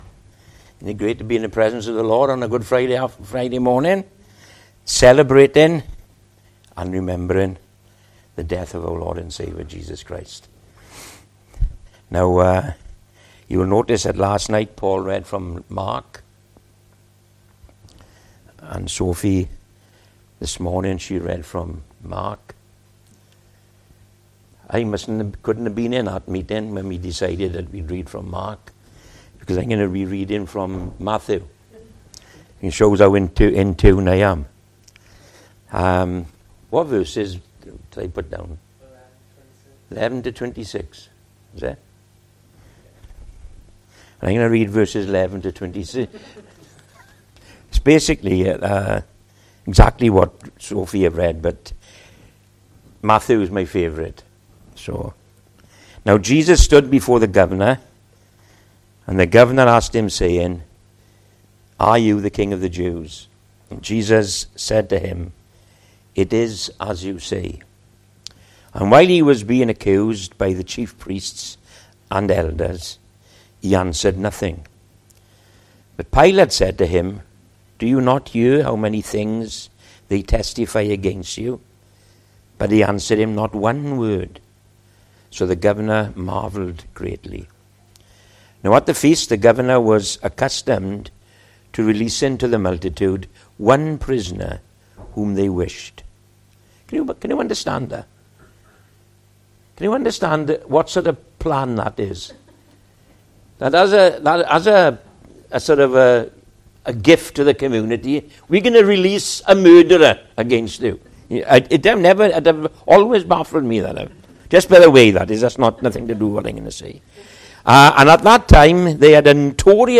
Sermons - Emmanuel Christian Fellowship